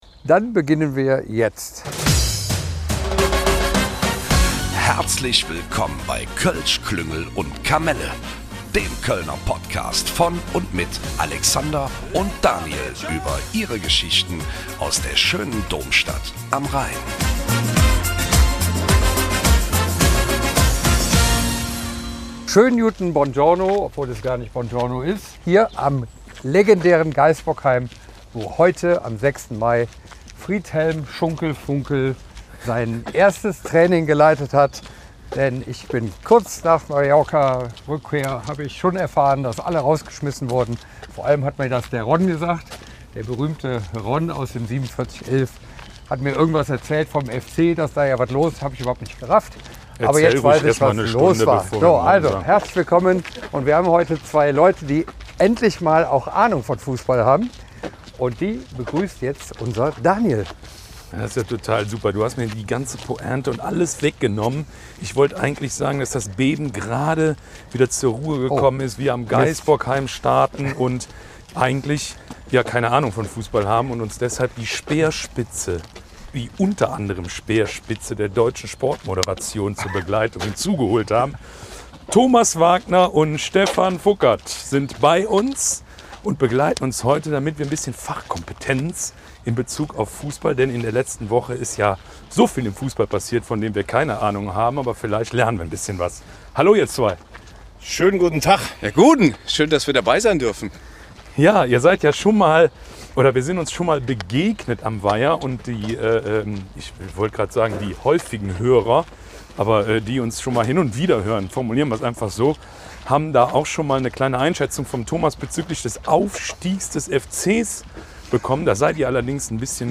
Beide sind auch bereits Podcaster, sodass wir neben den Personalveränderungen beim FC heute mal auch allgemein über Fussball, Fussballanalysen, Podcasts und natürlich den Weg zur Sportmoderation sprechen. Wir haben viel gelacht und auch ein paar Dinge über den Fussball gelernt .... hört rein und seht ob Ihr alles bereits wusstet.